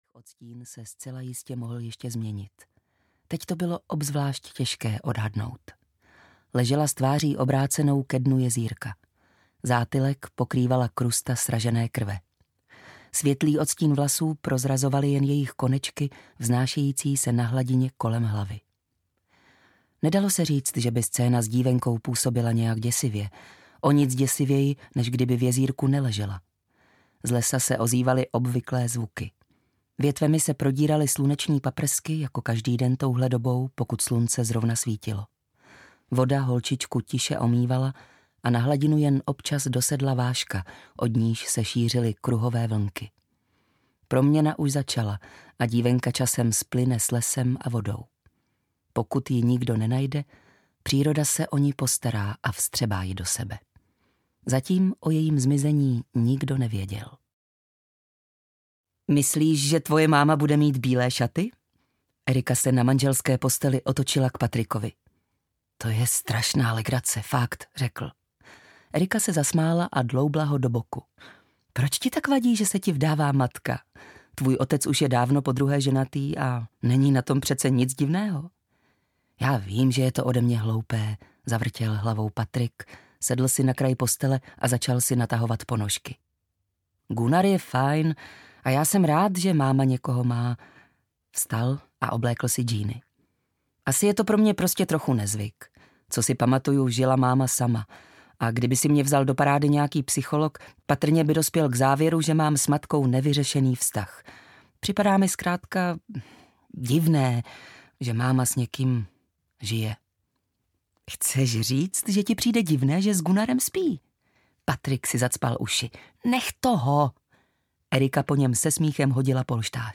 Čarodějnice audiokniha
Ukázka z knihy